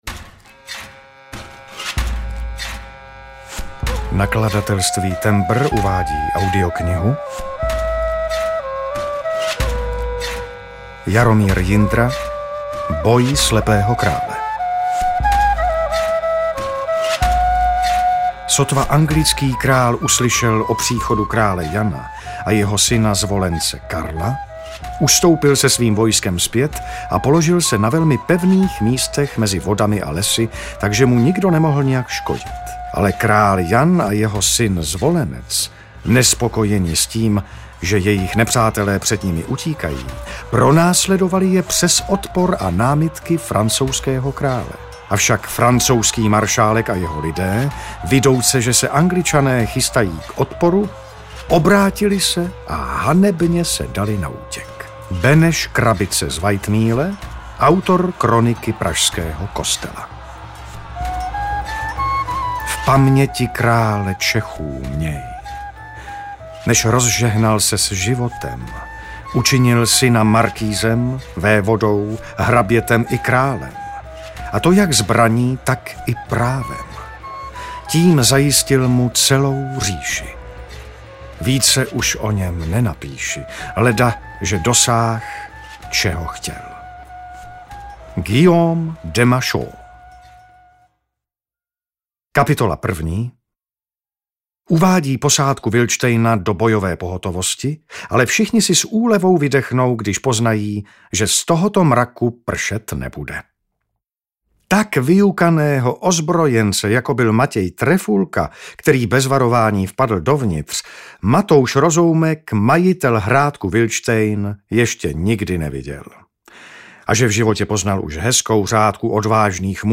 Boj slepého krále audiokniha
Ukázka z knihy